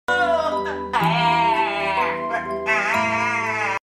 Twomad Cry Sound Effect Free Download
Twomad Cry